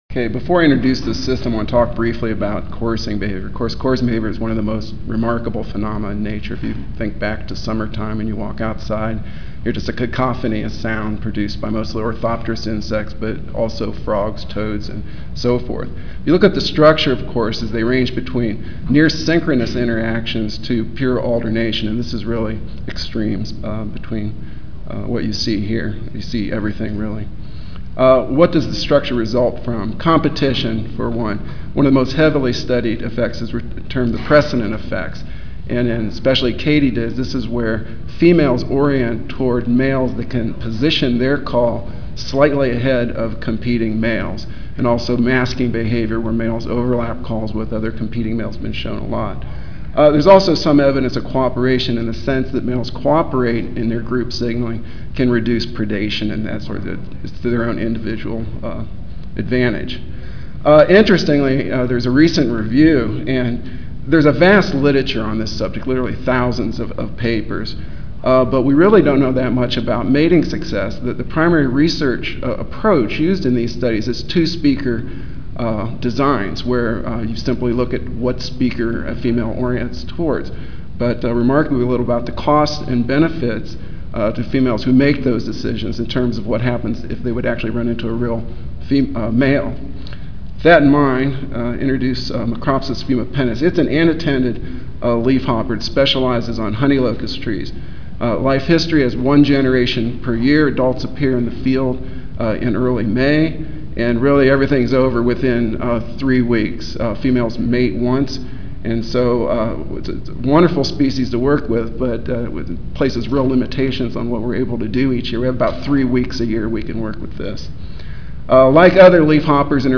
Room A5, First Floor (Reno-Sparks Convention Center)
Ten Minute Paper (TMP) Oral